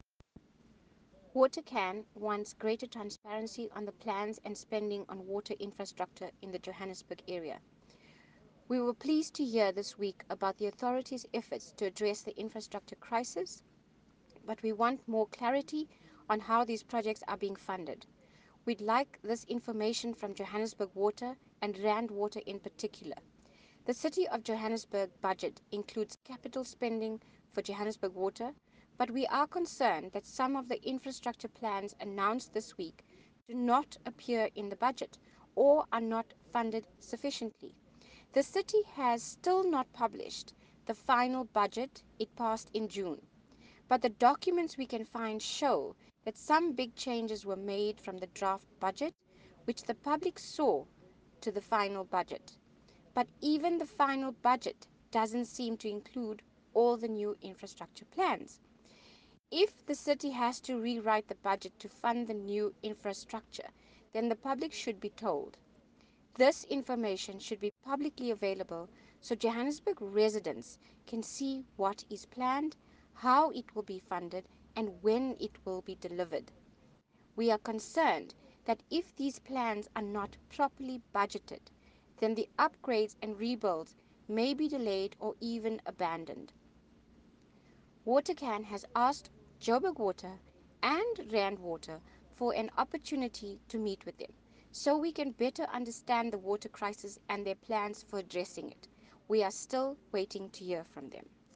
A voicenote